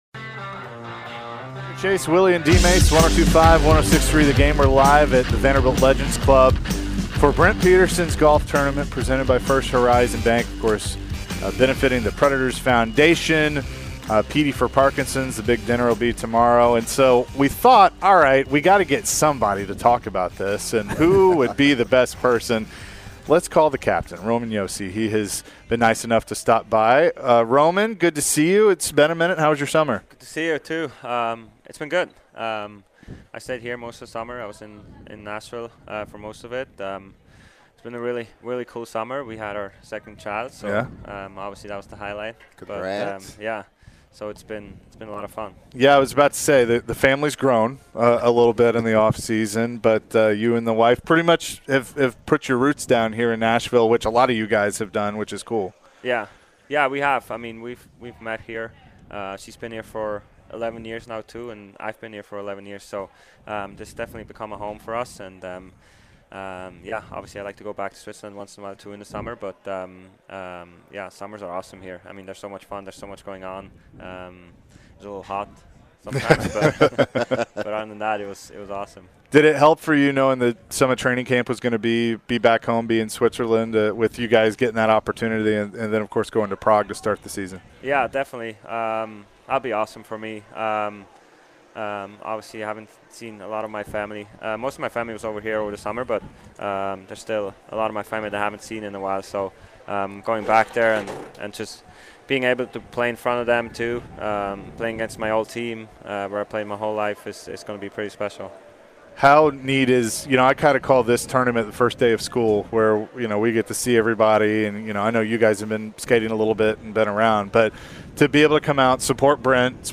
Roman Josi interview (9-12-22)